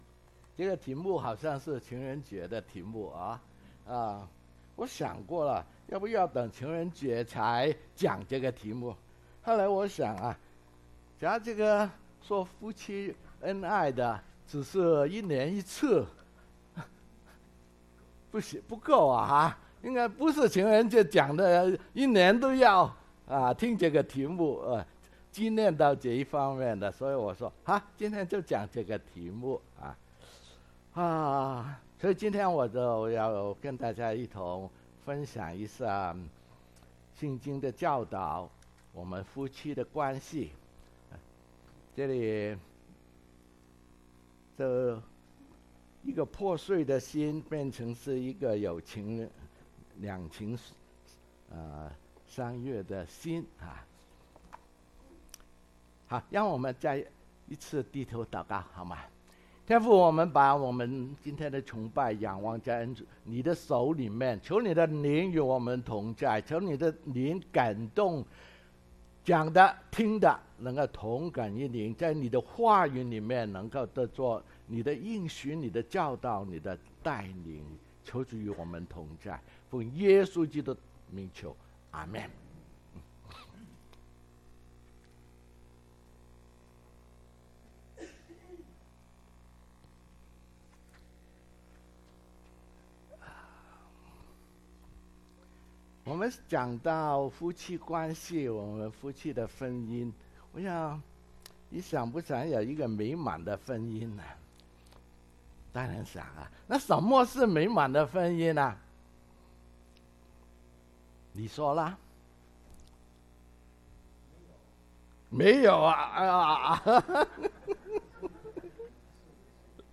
講員